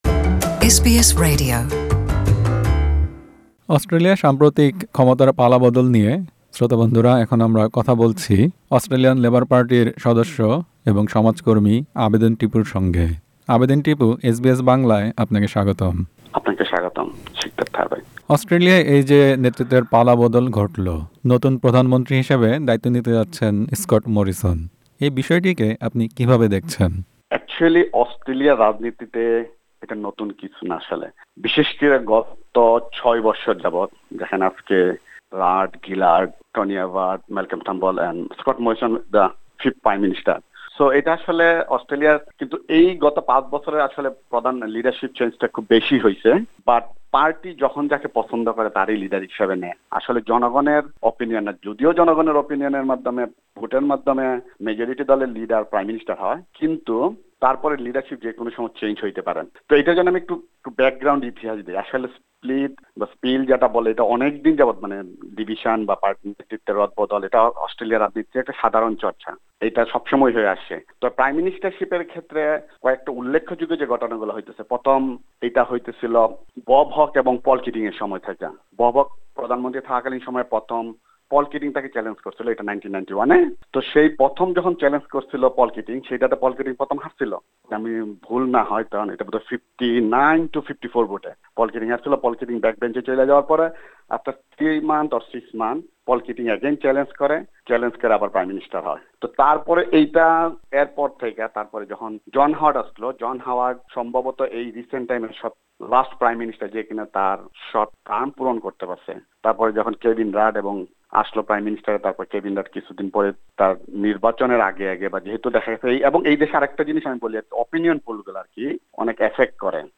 Leadership spill: Interview